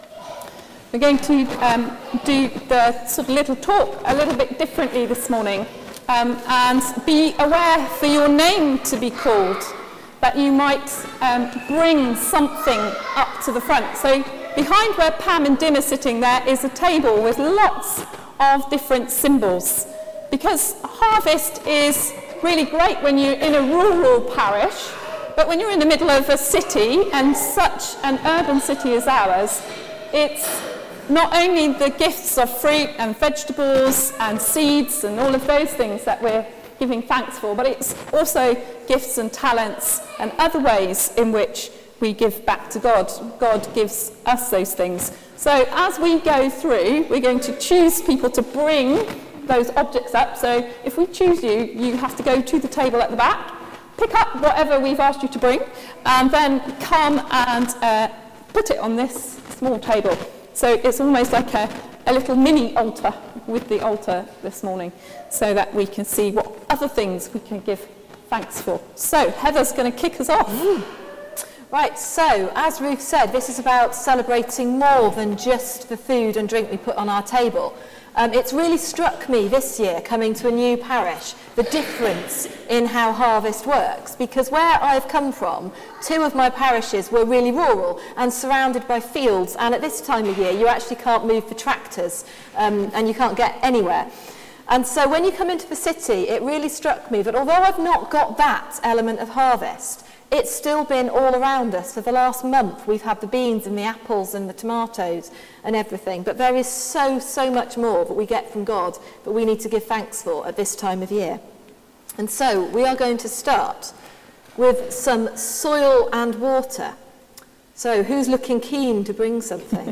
Sermon: Harvest: Celebrating more than just food | St Paul + St Stephen Gloucester